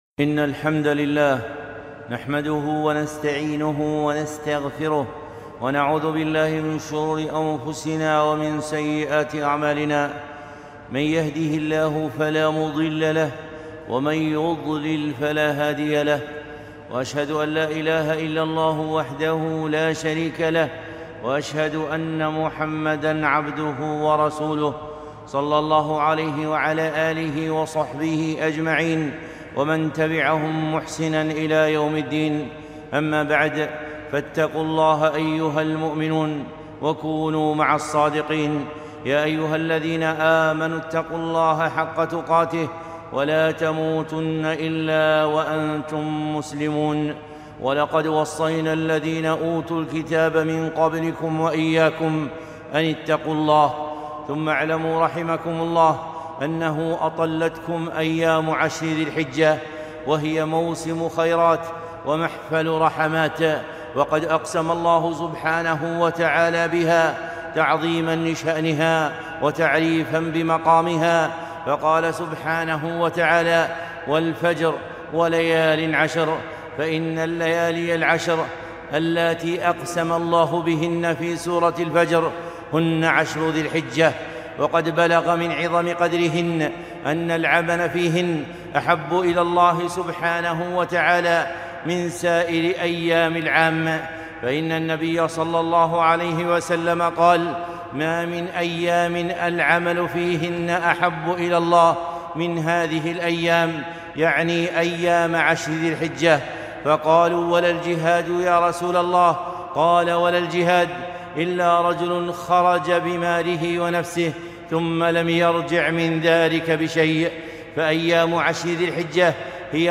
خطبة - عشر الخيرات ٢٦ ذو القعدة ١٤٤١ هــ